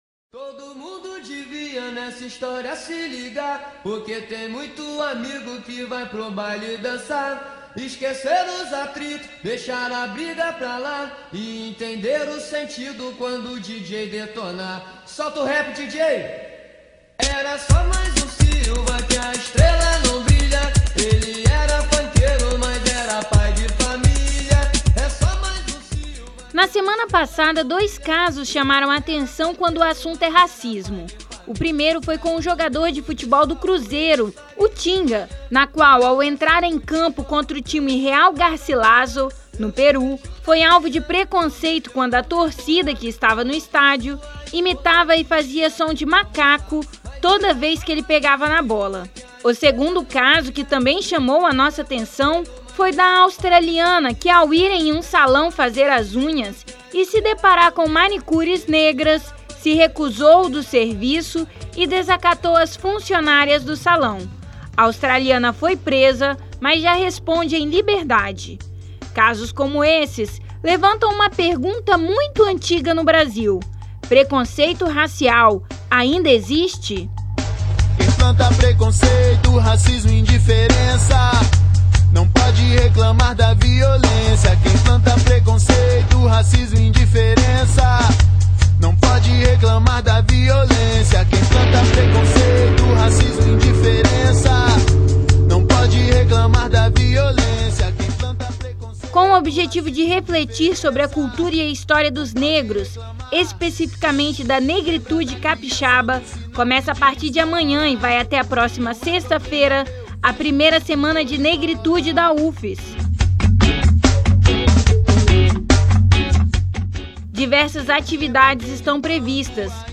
O Revista Universitária preparou uma matéria.